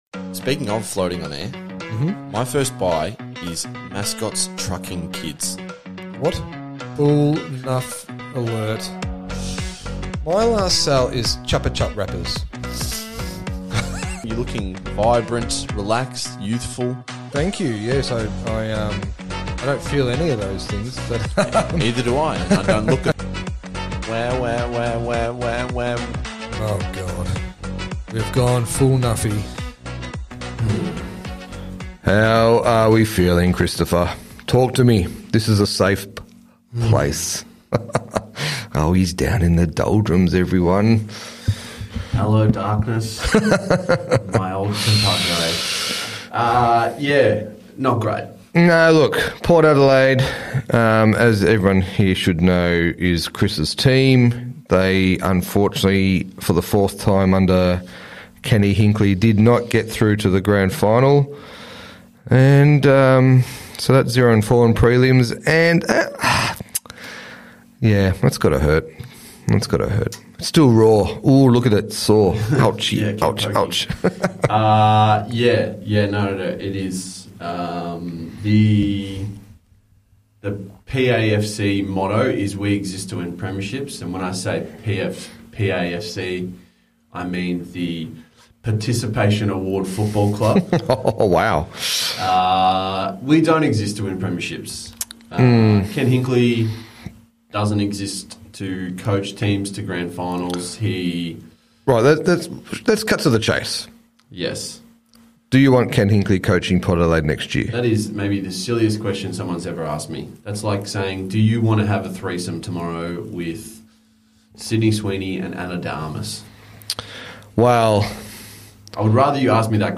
What do you get when a Sports Trader and Sports Creative sit down to chew the fat?